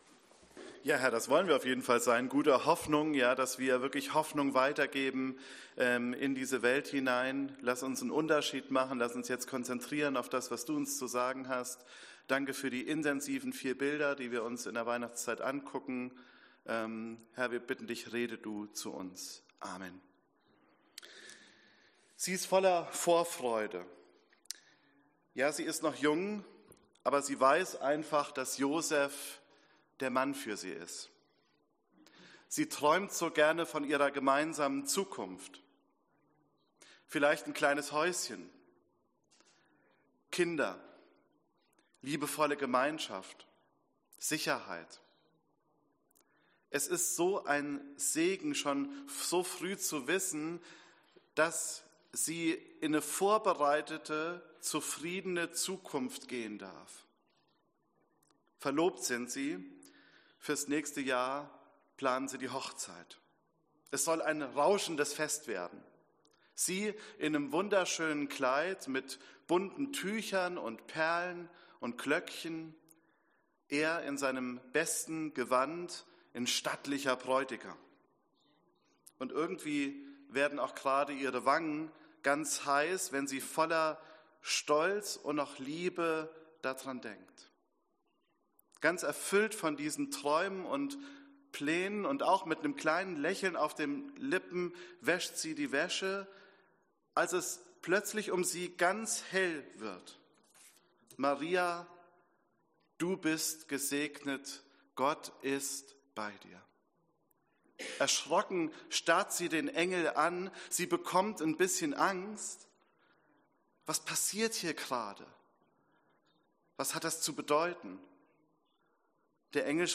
Predigt C1 - Christus zuerst, eins in Christus